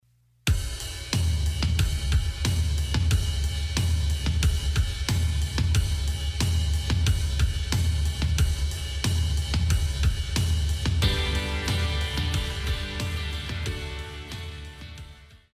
套鼓(架子鼓)
乐团
演奏曲
流行音乐,英伦摇滚
独奏与伴奏
有主奏
有节拍器